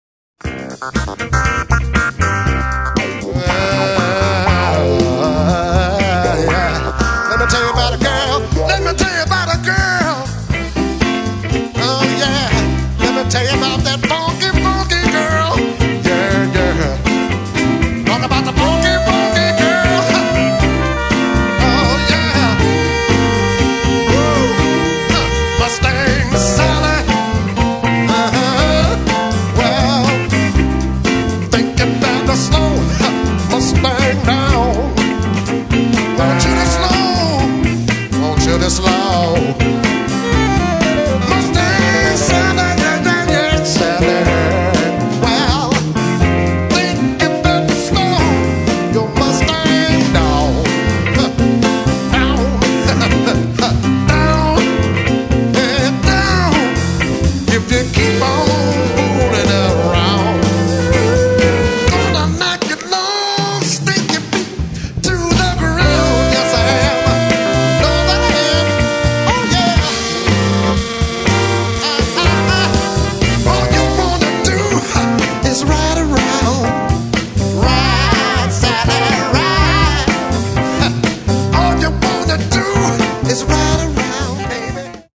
Motown/R&B/Disco